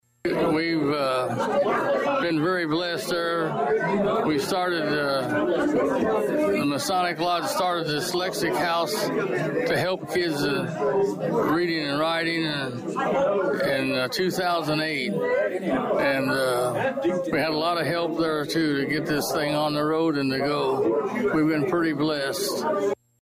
The occasion was an early birthday reception for Jones, who was Danville’s mayor from 1987 until 2003.
The reception honoring Jones took place prior to the regular city council meeting.